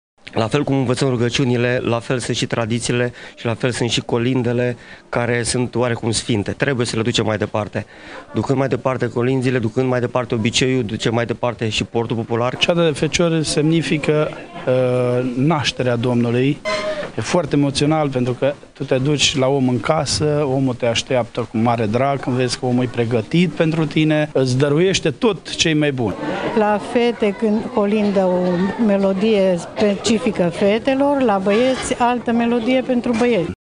Iată ce spun tinerii colindători despre tradițiile locului:
VOXURI-CEATA-FECIORI.mp3